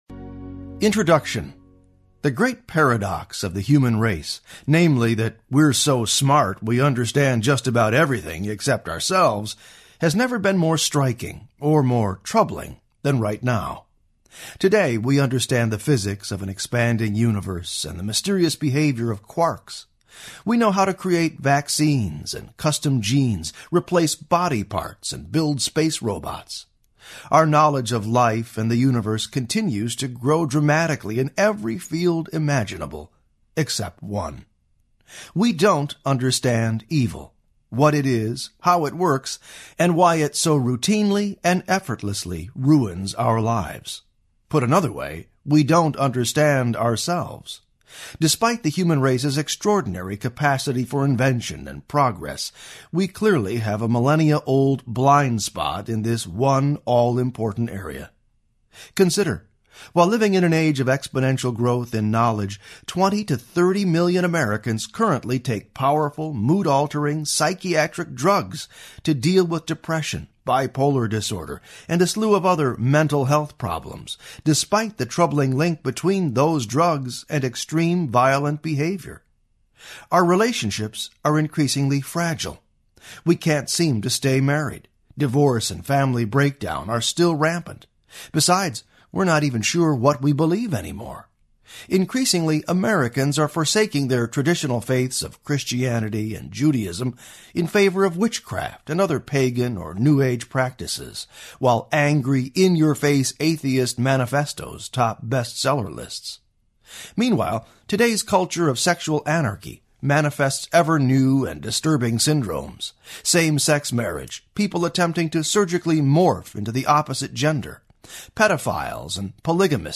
How Evil Works Audiobook
Narrator
9.1 Hrs. – Unabridged